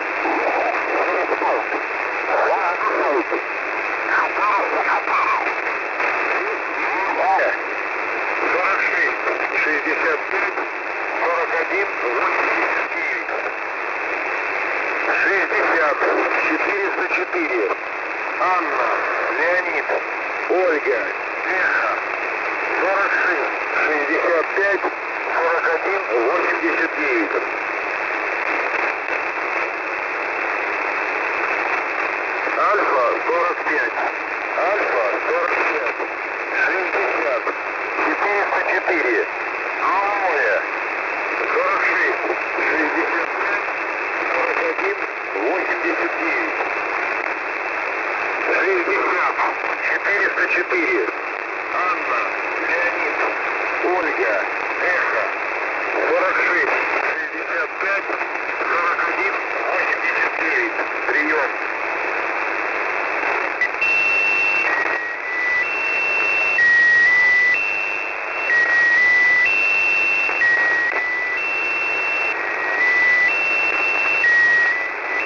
Mode: USB